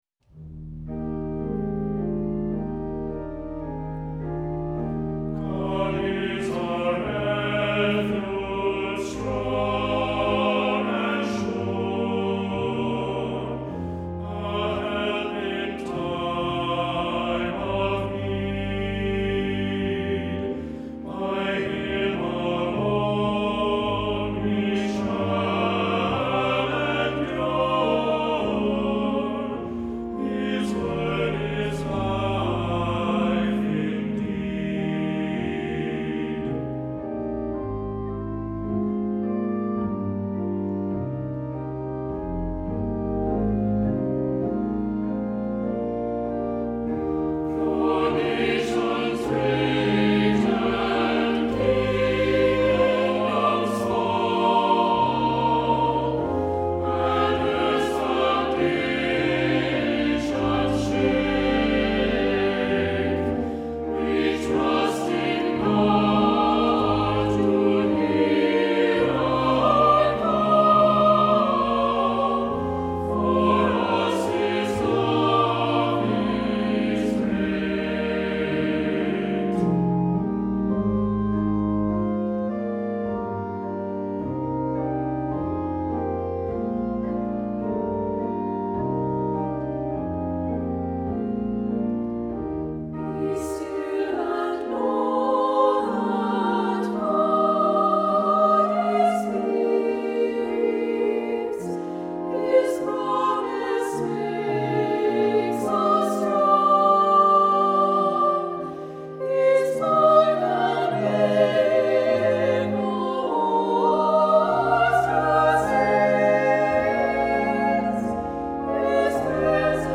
Voicing: SATB, Assembly and Organ